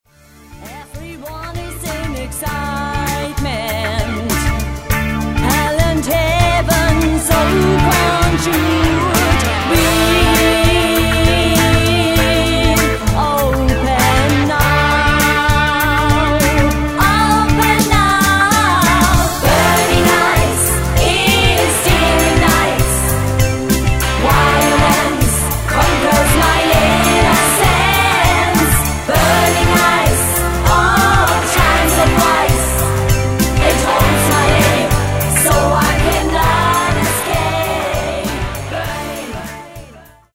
Stil: Rock-Pop
Eingängiger Rock-Popsong
mit Ohrwurmqualitäten